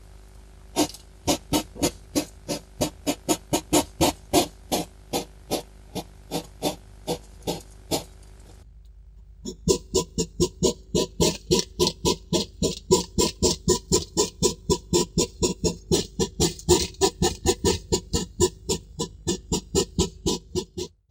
During aggressive encounters, such as agonistic displays and fights, male hedgehogs make a loud, rasping snorting noise.
aggressive_hedgehog_snorting.mp3